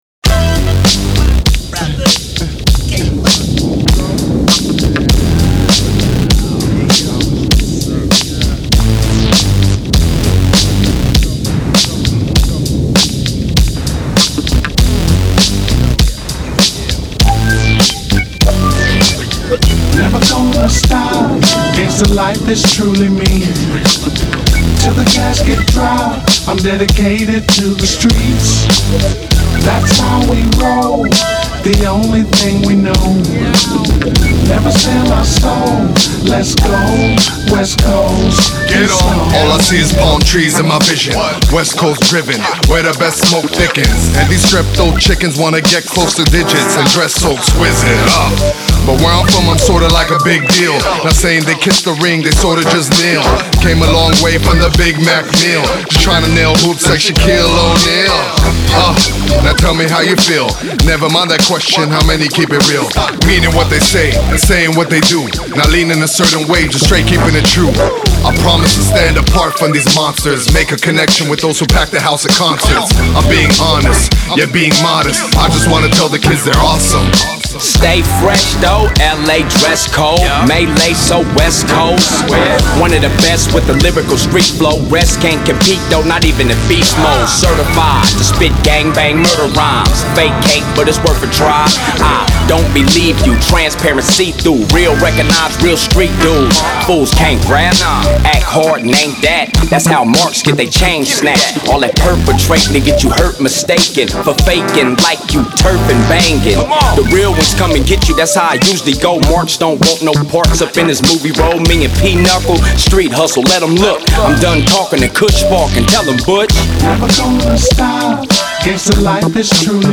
With a strong vocal tone and a demanding presence